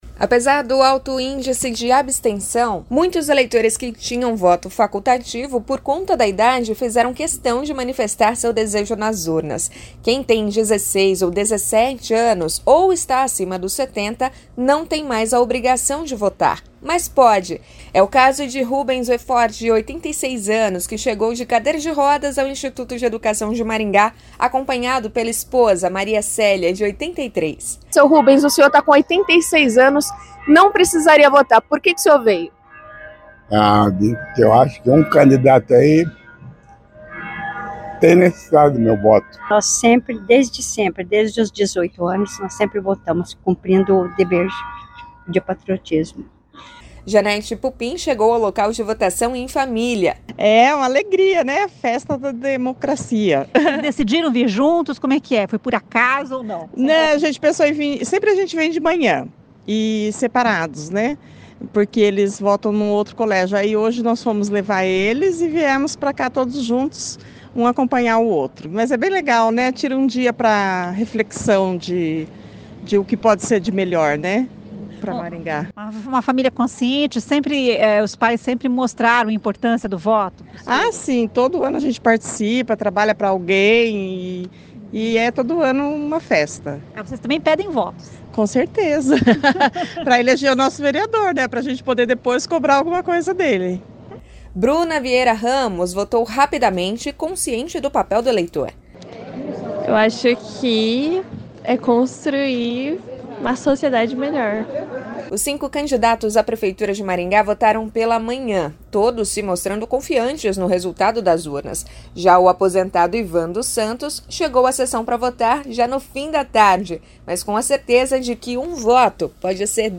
O juiz eleitoral Rafael Altoé avaliou que a votação na cidade foi bastante tranquila. [ouça o áudio acima]
Edson Scabora (PSD), atual vice-prefeito de Maringá, que ficou em segundo lugar na disputa eleitoral, com 21,89% dos votos, comentou o resultado. [ouça o áudio acima]